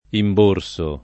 imborso [ imb 1 r S o ]